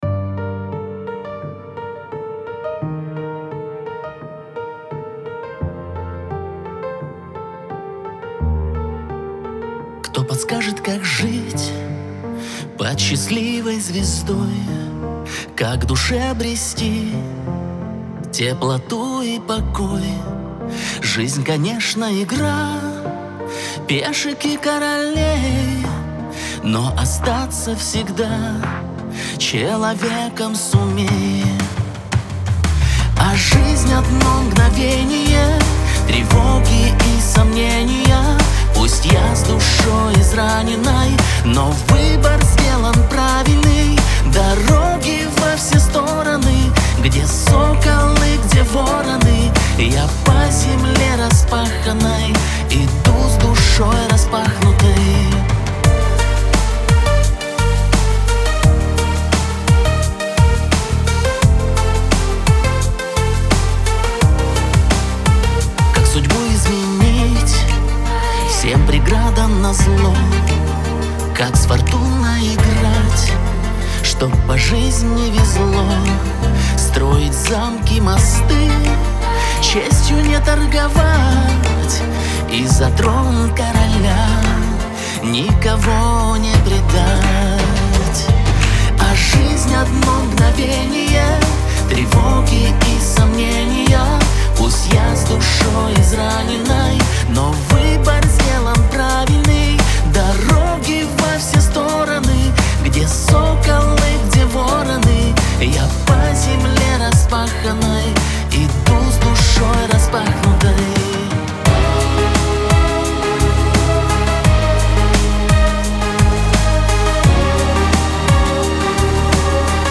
эстрада , Лирика
pop
грусть